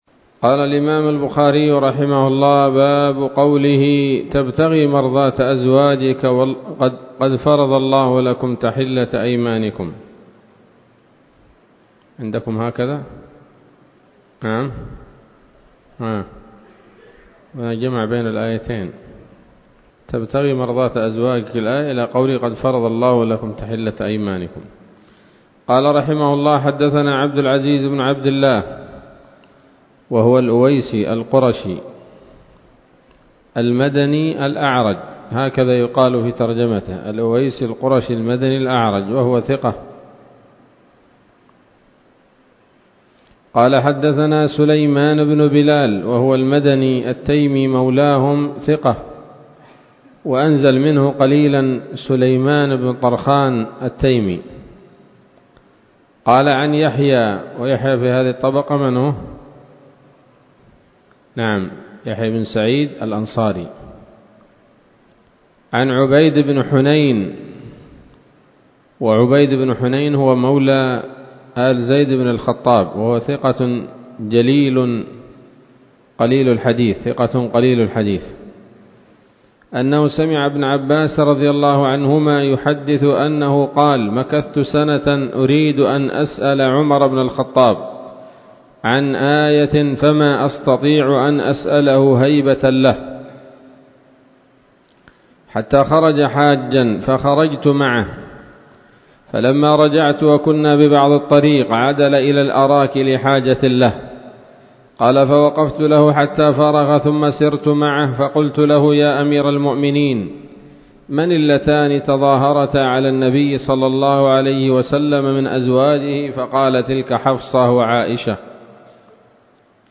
الدرس التاسع والستون بعد المائتين من كتاب التفسير من صحيح الإمام البخاري